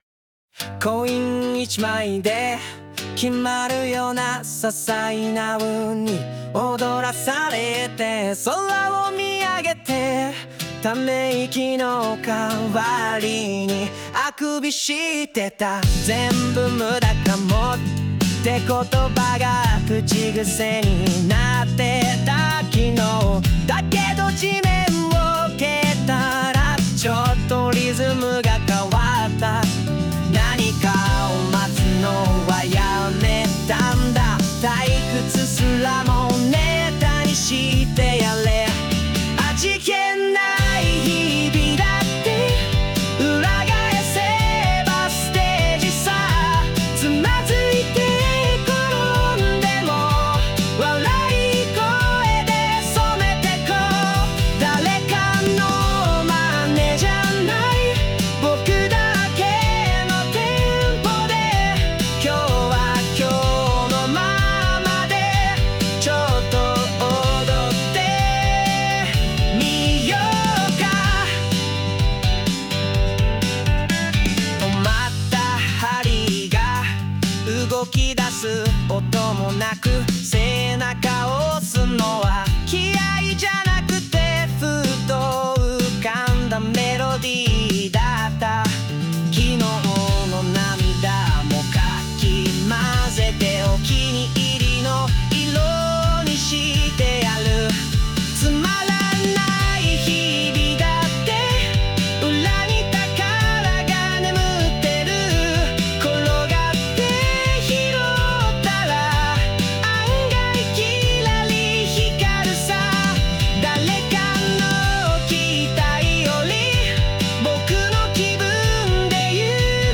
邦楽男性ボーカル著作権フリーBGM ボーカル
著作権フリーオリジナルBGMです。
男性ボーカル（洋楽・英語）曲です。